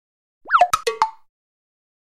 SFX出糗音效下载
SFX音效